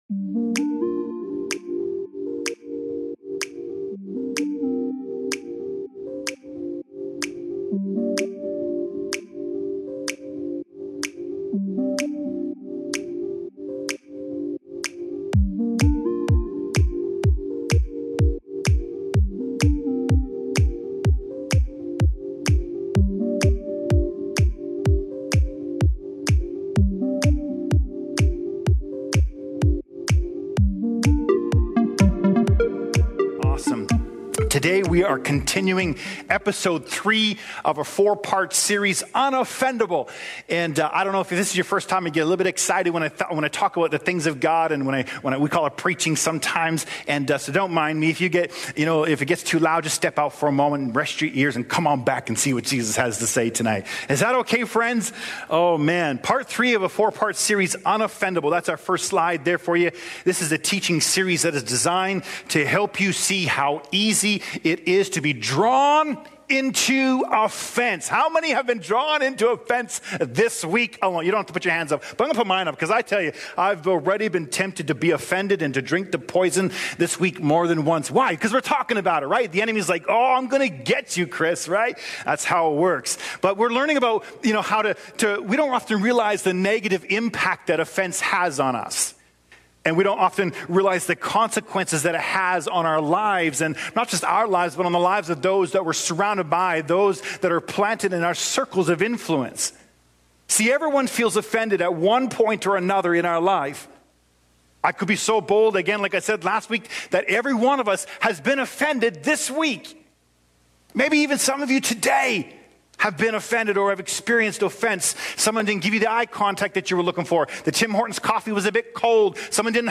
Sermons | Beloved City Church